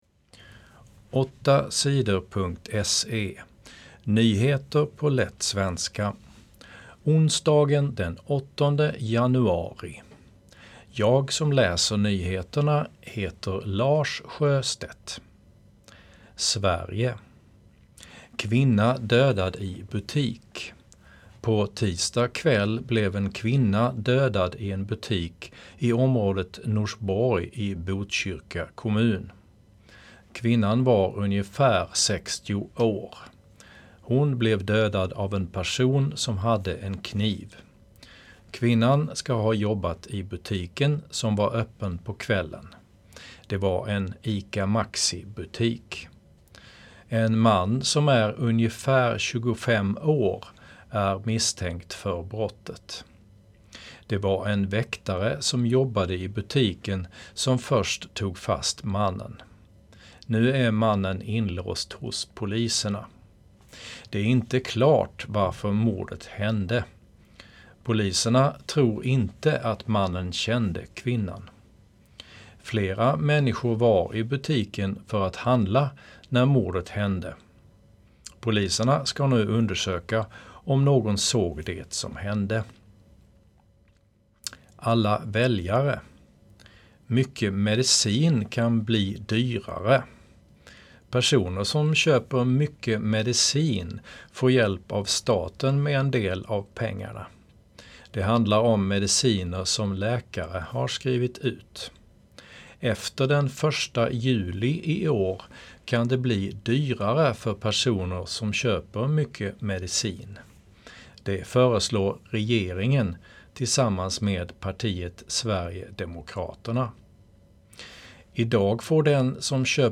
Nyheter på lätt svenska 8 januari